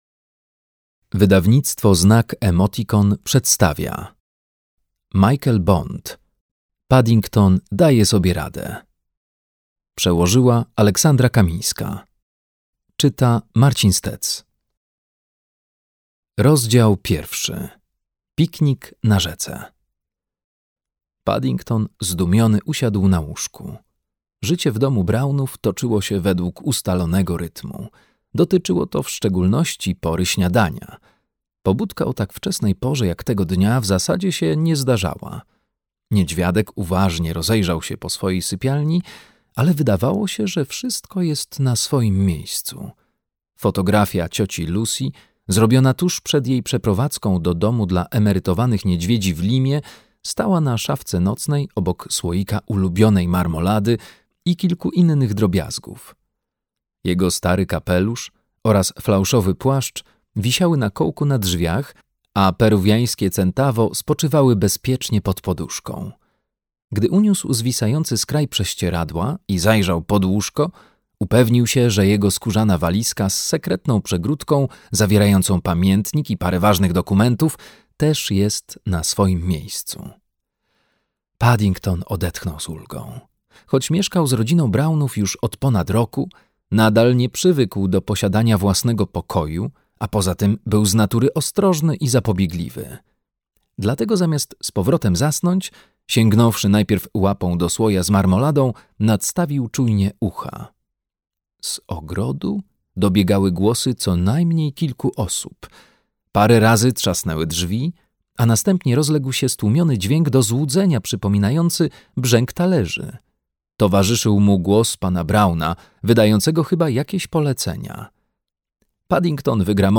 Paddington daje sobie radę - Michael Bond - audiobook